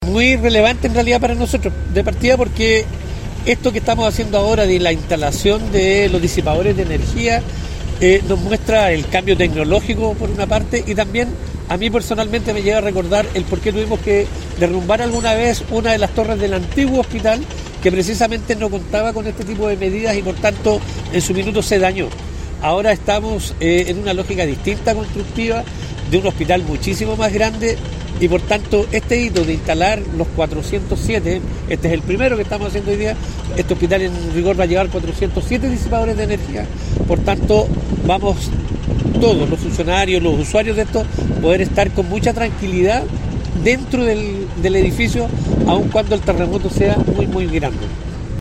En esta línea, el Directos del Servicio de Salud Coquimbo, Ernesto Jorquera, acotó que
AISLANTE-SISMICO-Ernesto-Jorquera-Director-Servicio-de-Salud-Coquimbo.mp3